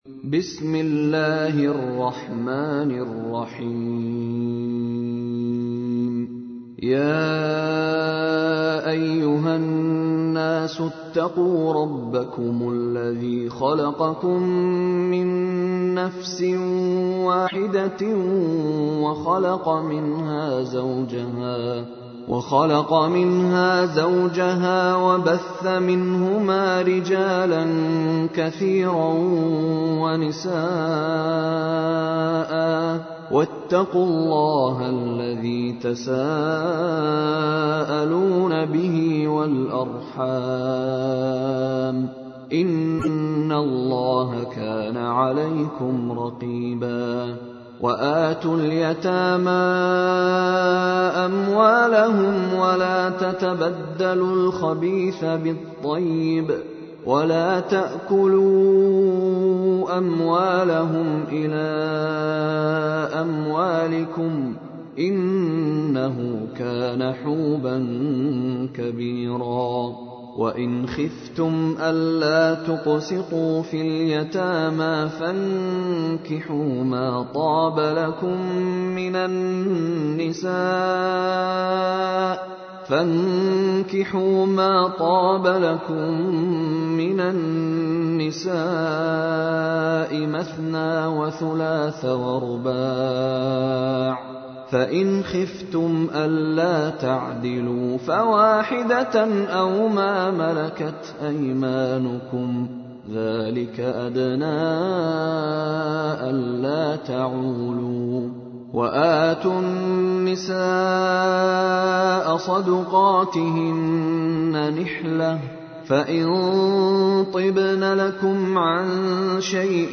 تحميل : 4. سورة النساء / القارئ مشاري راشد العفاسي / القرآن الكريم / موقع يا حسين